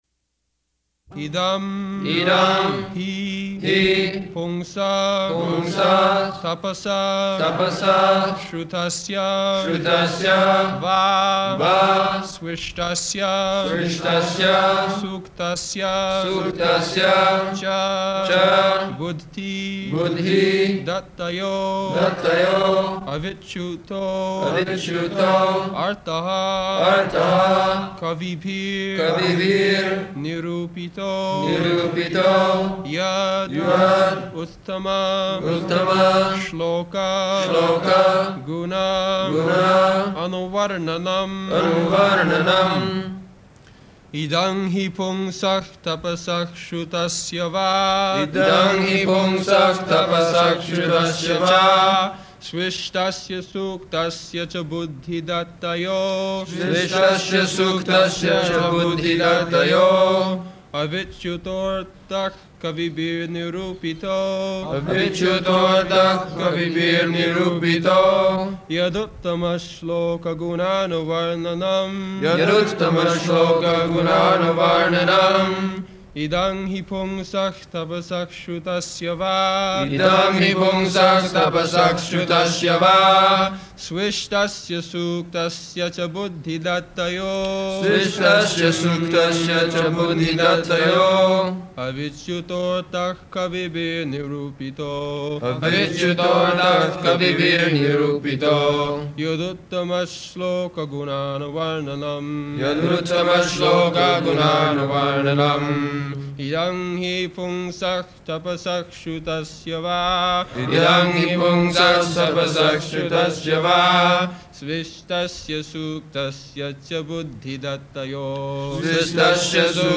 -- Type: Srimad-Bhagavatam Dated: August 3rd 1974 Location: Vṛndāvana Audio file